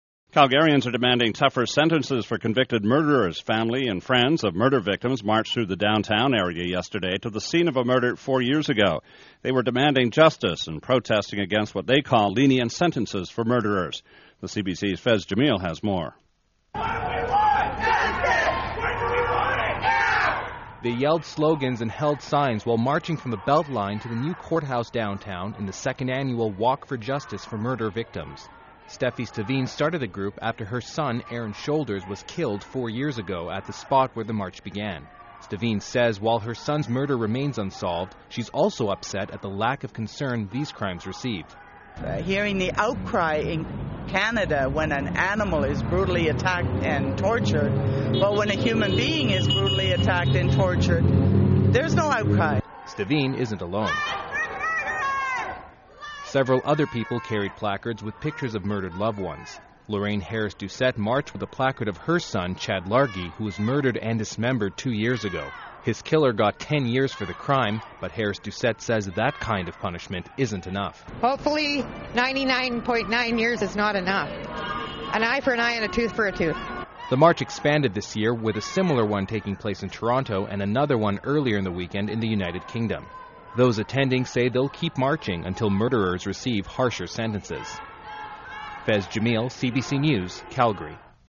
CBC Radio News clips, September 9th 2007: